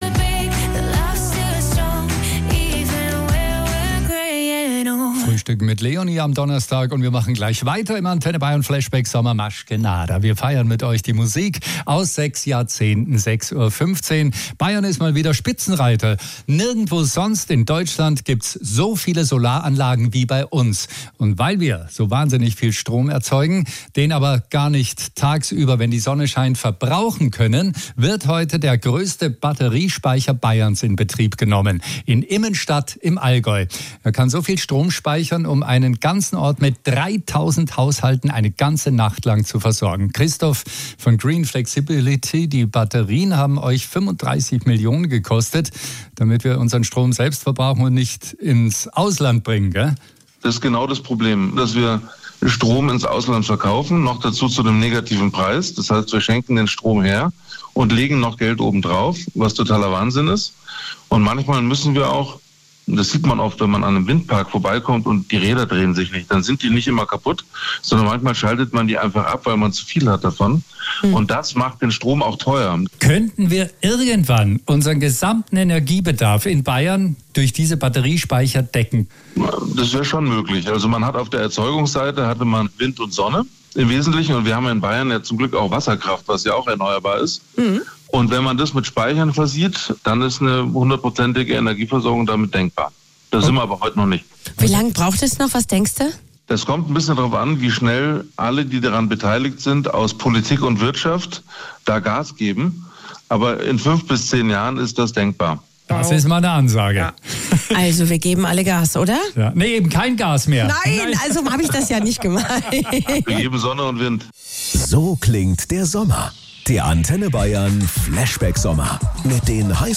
Radio Report Antenne Bayern